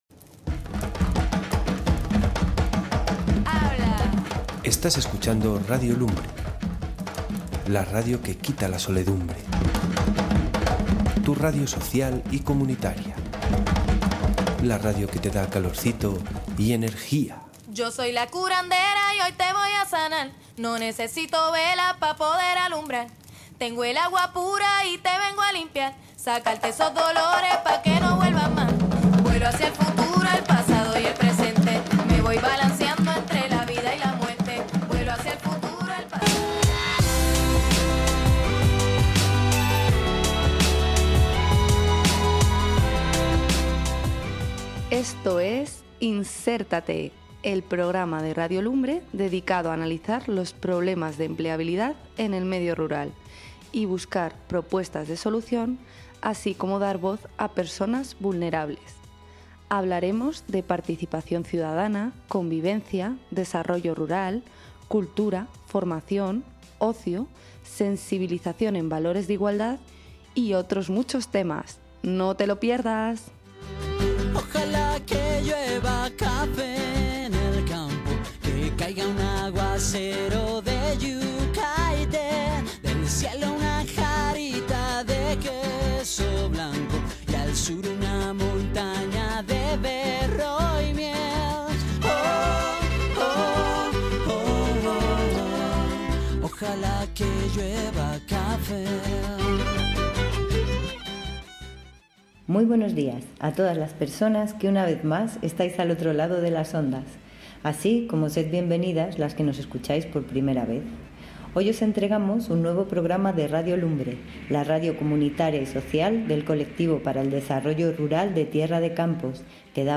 001. ENTREVISTA ASOCIACIÓN COOPERACILLO (Parte I)
entrevista-asociacion-cooperacillo-parte-i